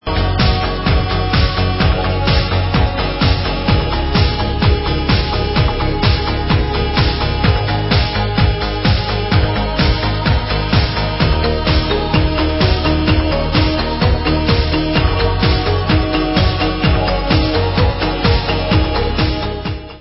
Synthie-pop